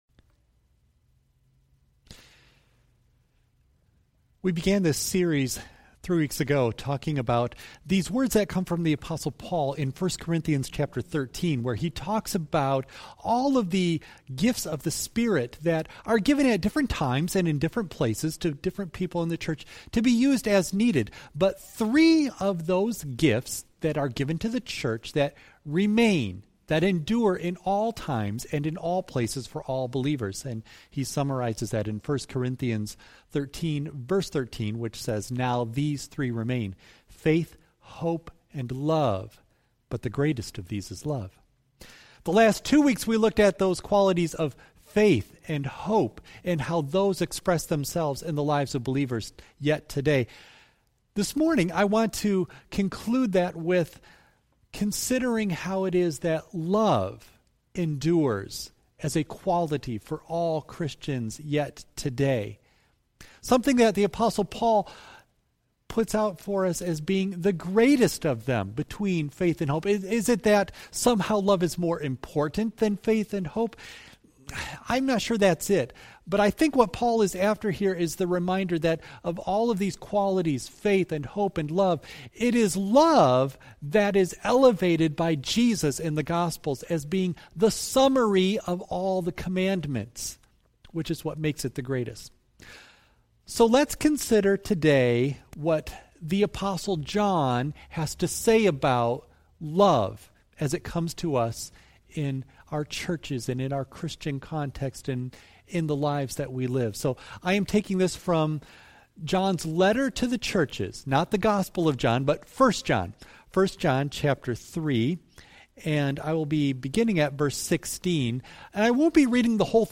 Worship Service May 31 Audio only of message